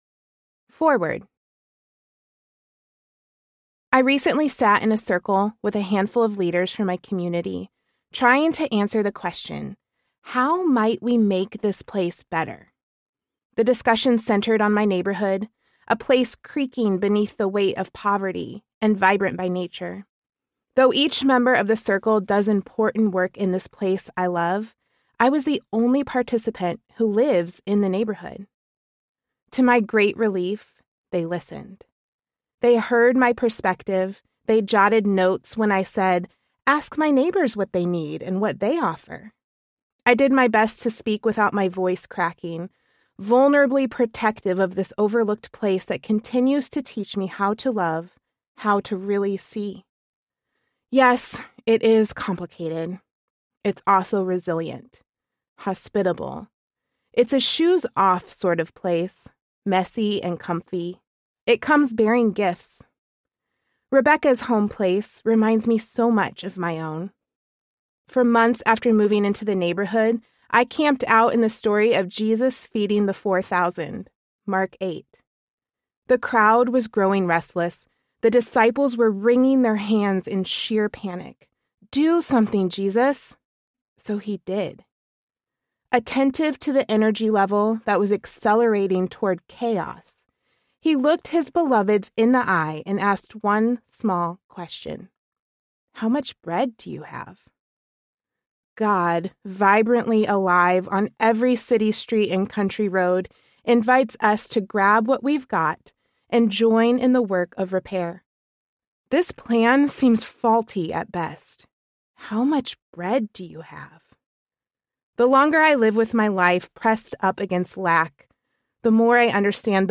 A Better Life Audiobook
6.2 Hrs. – Unabridged